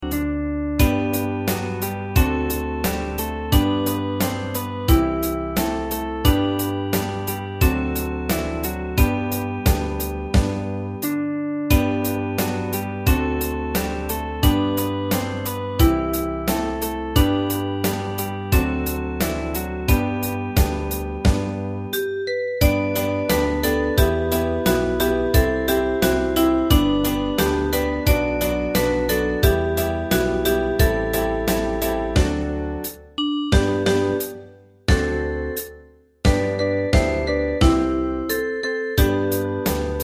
大正琴の「楽譜、練習用の音」データのセットをダウンロードで『すぐに』お届け！
カテゴリー: アンサンブル（合奏） .
童謡・唱歌・叙情歌